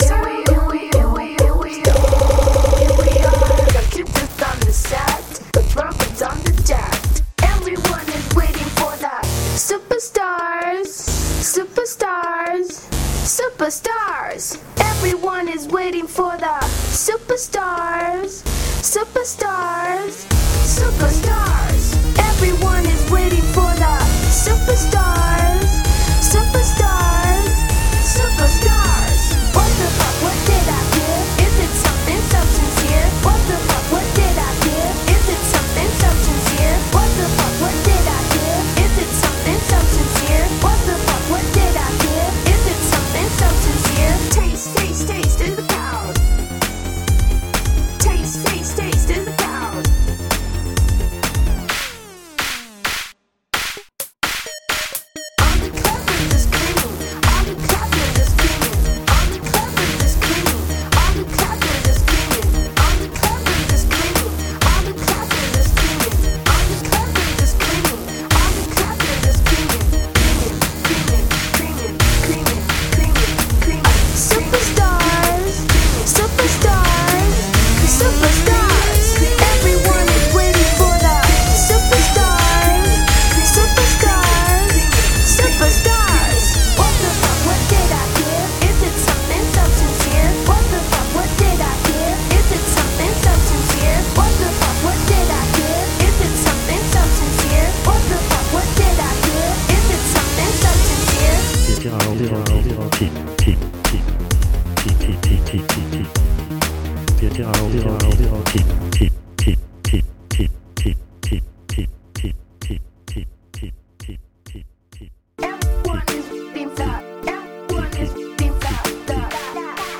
dalla voce molto Uffie.